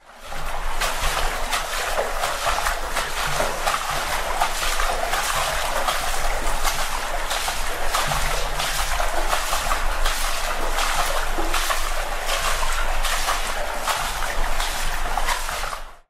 Hiệu ứng Âm thanh tiếng Bơi Lội ở hồ bơi mp3